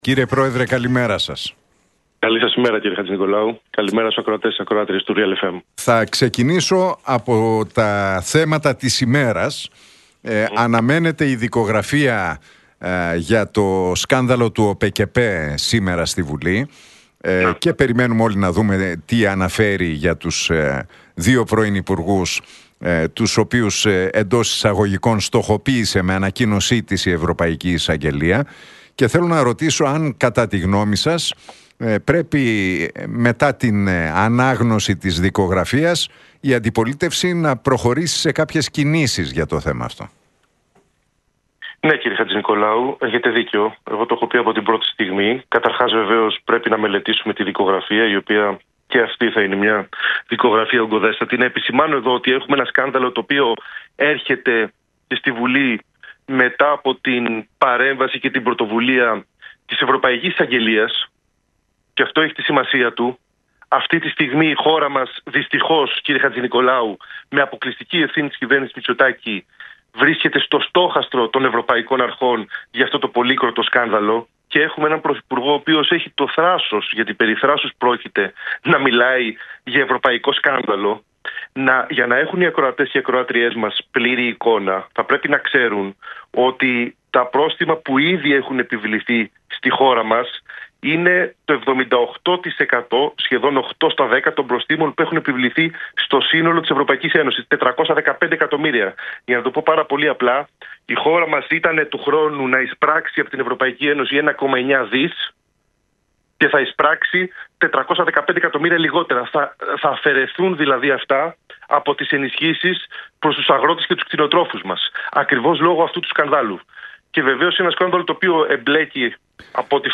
Για το σκάνδαλο με τις επιδοτήσεις στον ΟΠΕΚΕΠΕ και την Προανακριτική για τον Κώστα Καραμανλή μίλησε ο πρόεδρος της Νέας Αριστεράς, Αλέξης Χαρίτσης στον Νίκο Χατζηνικολάου από την συχνότητα του Realfm 97,8.